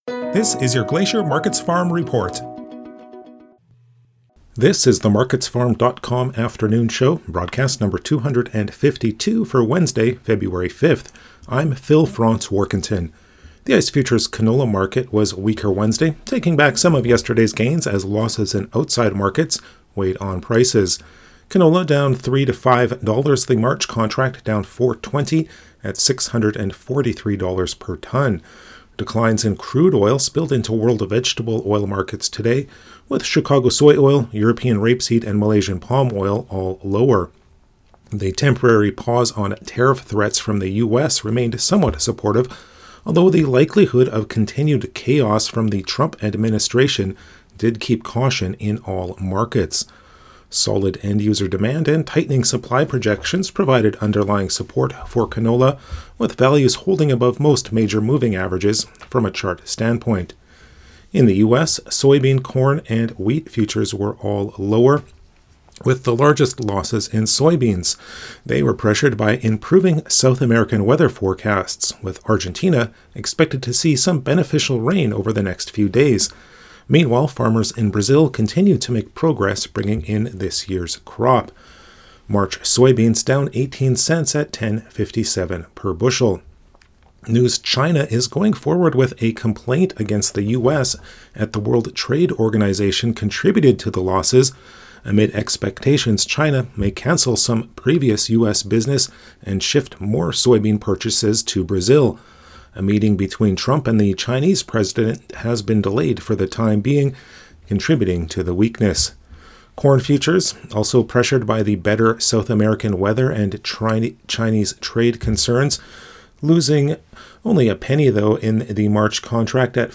MarketsFarm afternoon radio show, Feb. 5 - AgCanada